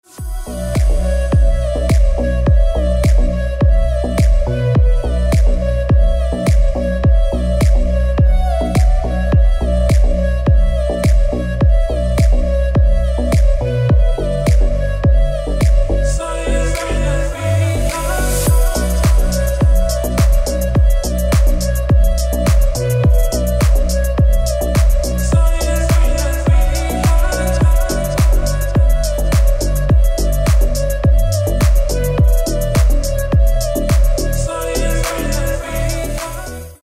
• Качество: 320, Stereo
громкие
deep house
восточные мотивы
атмосферные
dance
EDM
электронная музыка
Trap